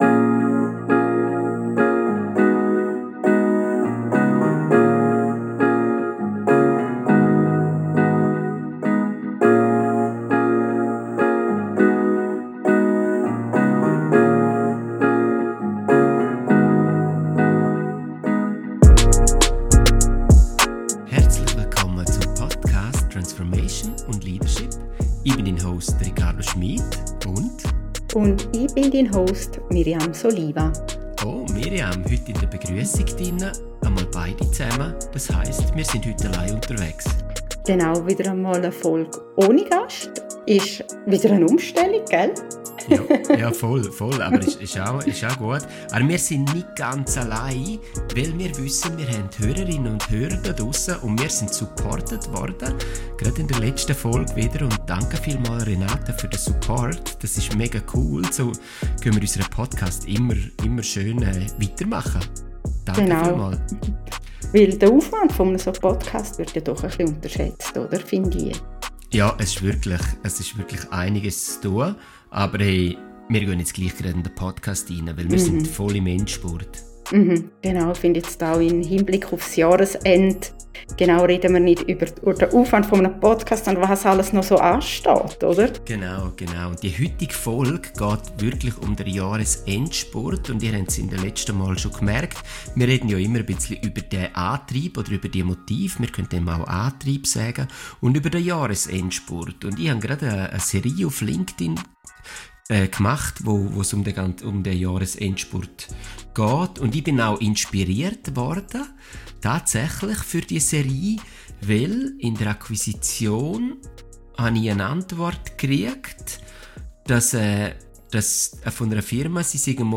Ein Gespräch.